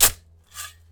Shovel Sound
A short sound of a shovel moving dirt.
shovel_0.ogg